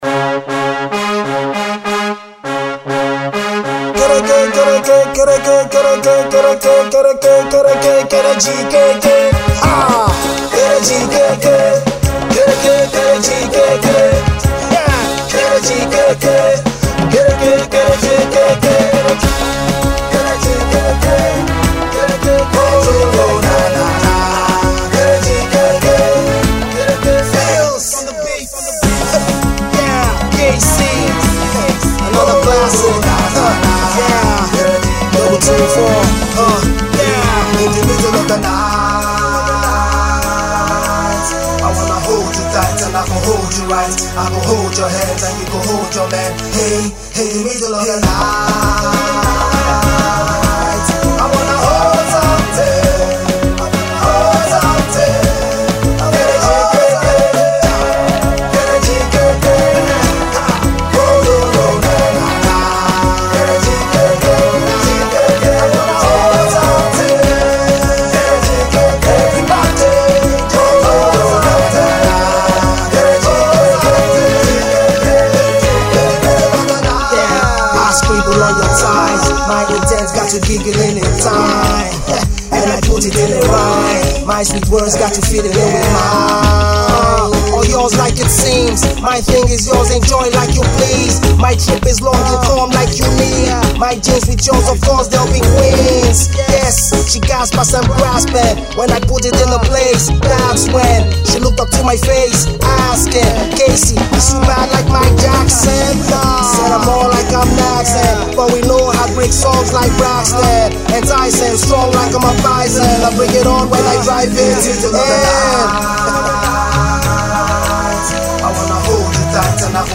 Afro Hip-Hop artist
It’s a sure club banger.